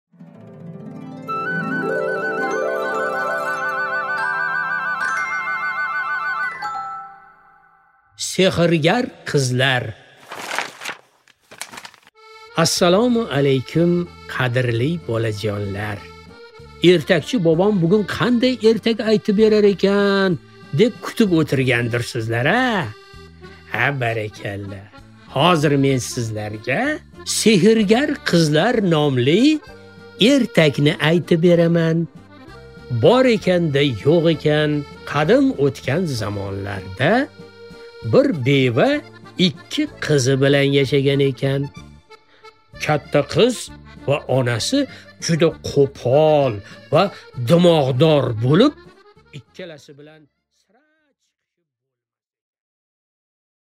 Аудиокнига Sehrgar qizlar